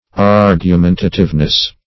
Ar`gu*men"ta*tive*ness, n.